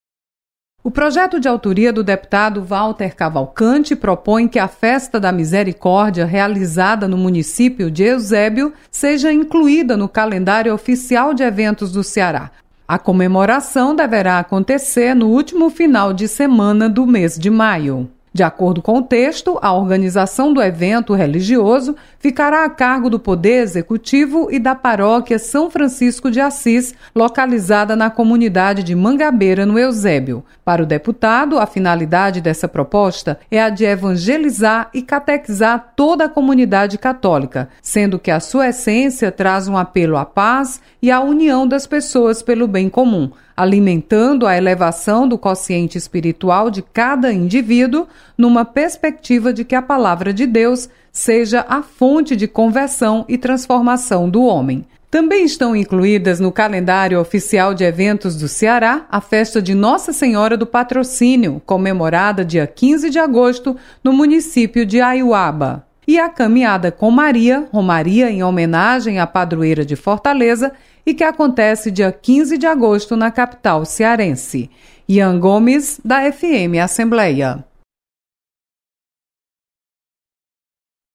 Projeto amplia festa religiosa da Região Metroplitana de Fortaleza. Repórter